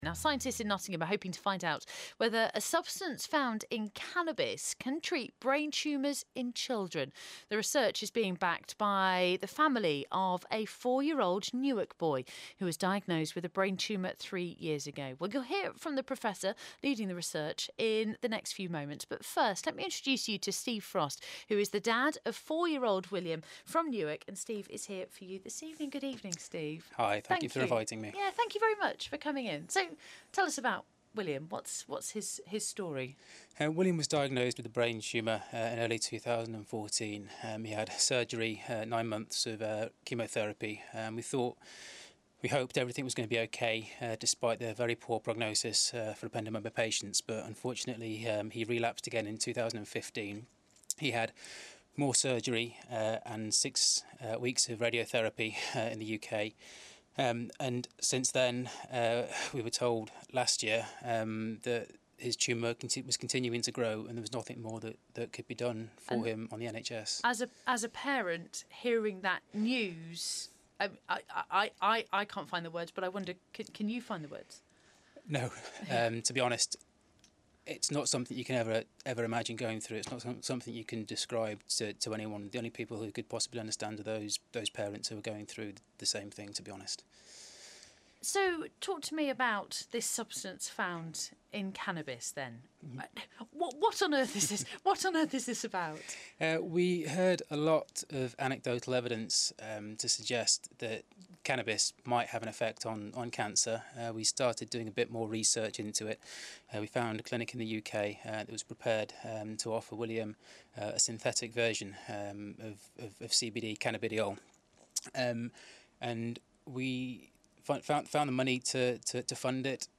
BBC Radio Interview Recording
Full recording of the CBD Research Project radio interview…